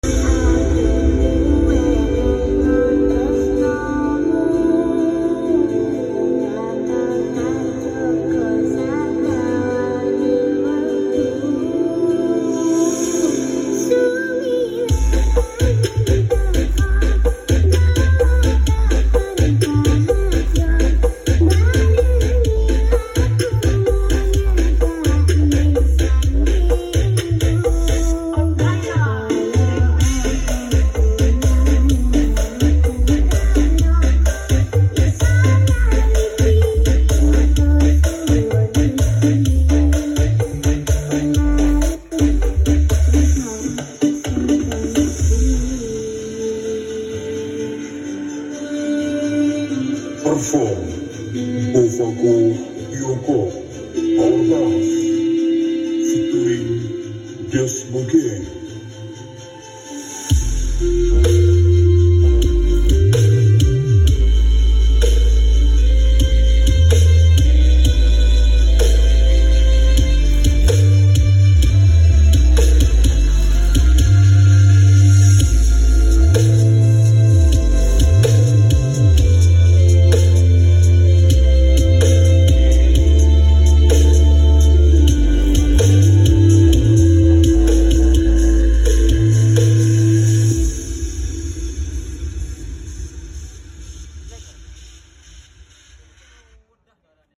Cek sound S3 audio langsung sound effects free download